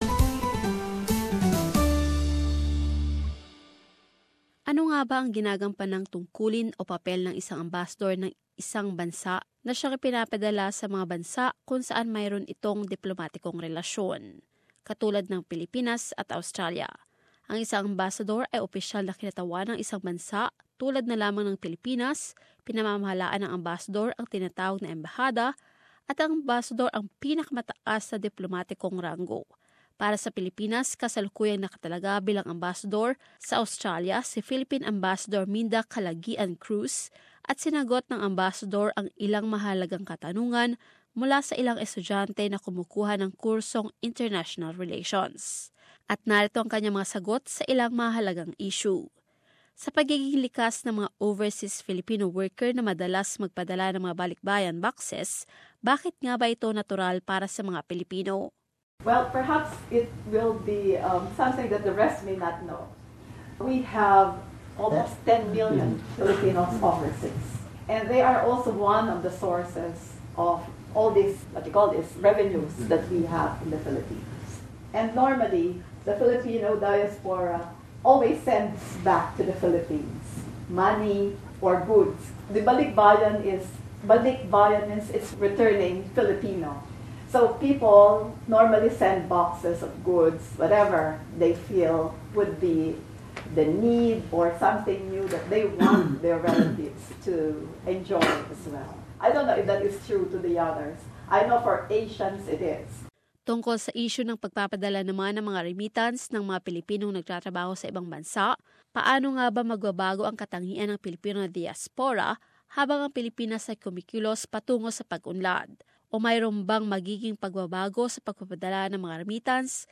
In her capacity as Philippine's highest ranking official in Australia, Philippine Ambassador Minda Calaguian-Cruz answers questions on Filipino remittance, continues migration and issues relating to the South China Sea.